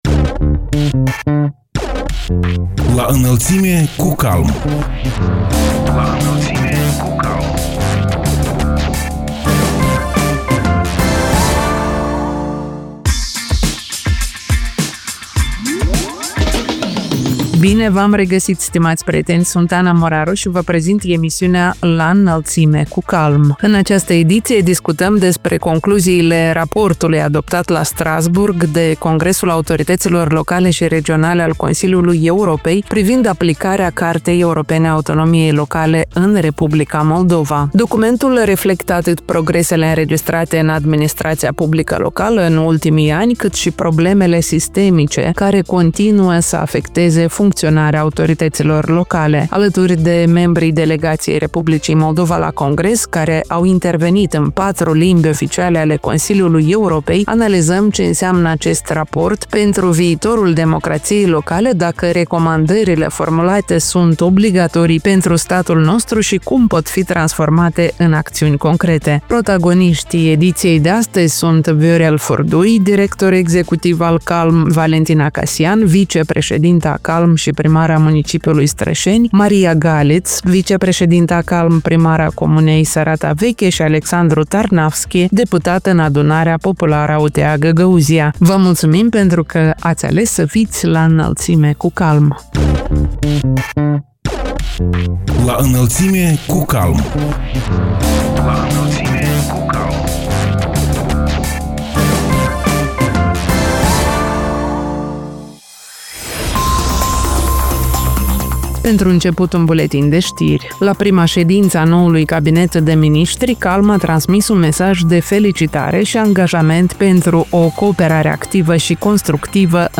Alături de membrii delegației Republicii Moldova la Congres, care au intervenit în patru limbi oficiale ale Consiliului Europei, analizăm ce înseamnă acest raport pentru viitorul democrației locale, dacă recomandările formulate sunt obligatorii pentru statul nostru și cum pot fi transformate în acțiuni concrete.